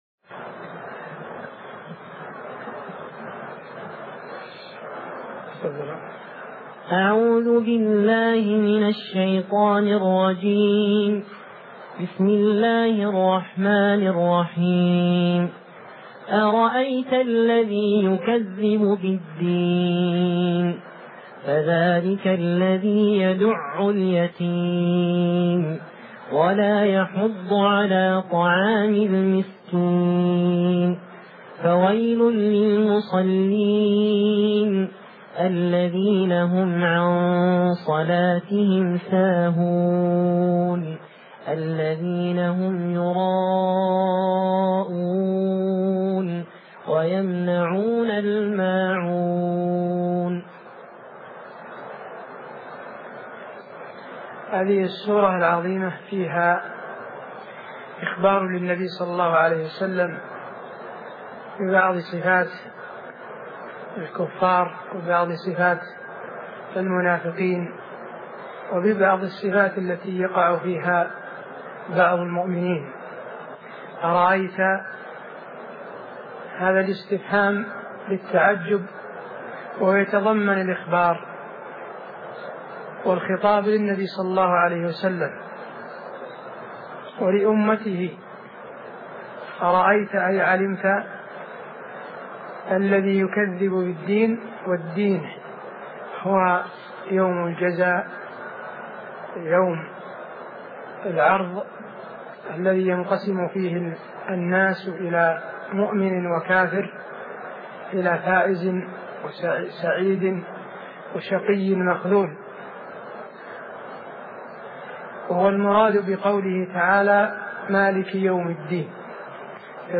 تفسير جزء عم لفضيلة الشيخ حسين آل الشيخ من دروس الحرم المدنى الشريف